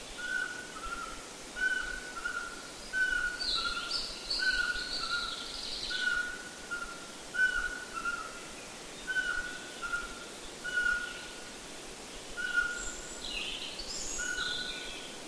Sperlingskauz
Der markante, hell pfeifende Revierruf des Männchens ist über einen Kilometer weit zu hören.
Audiodatei Sperlingskauz Gesang im Frühling
Sperlingskauz_Gesang_im_Fruehling.mp3